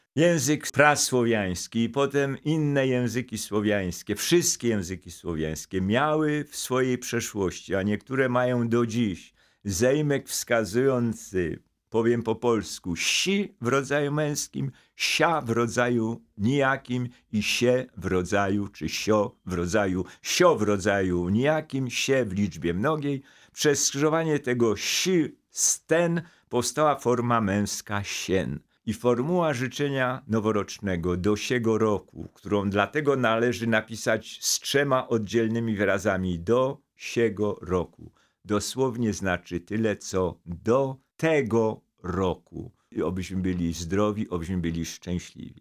Językoznawca, prof. Jan Miodek odwiedził w pierwszy dzień świąt studio Radia Rodzina.